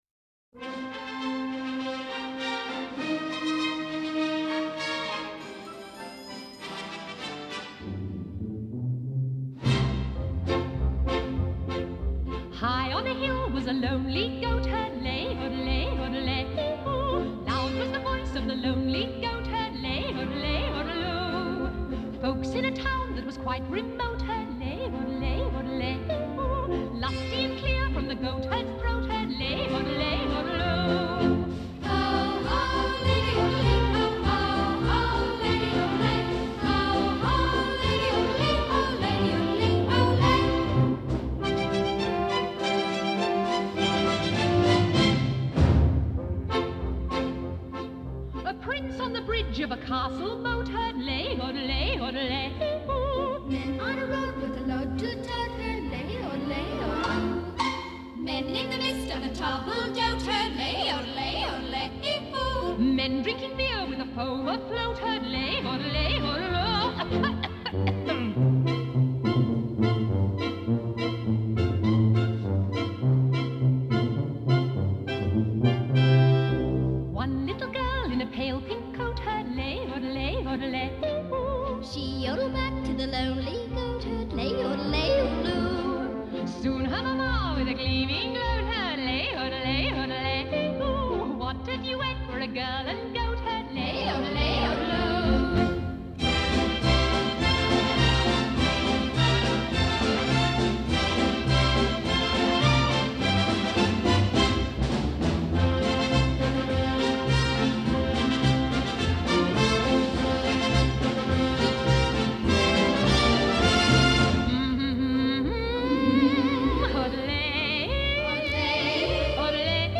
From the soundtrack album